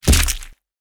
face_hit_finisher_40.wav